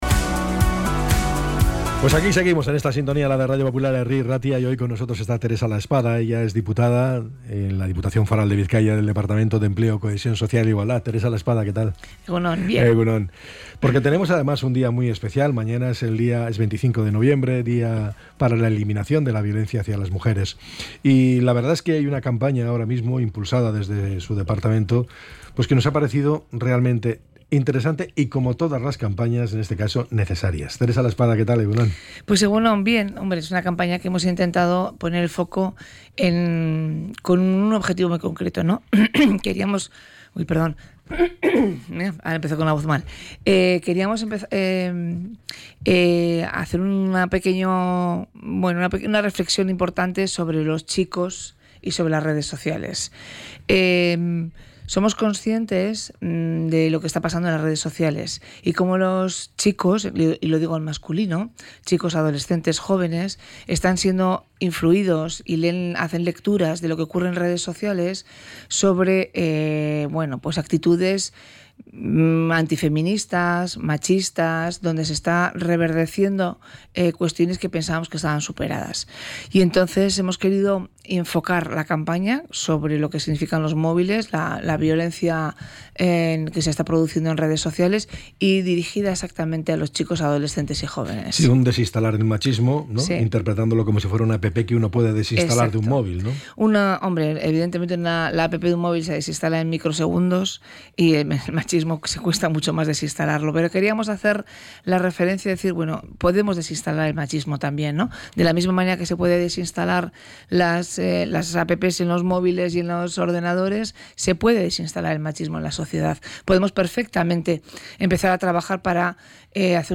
ENTREV.-TERESA-LAESPADA.mp3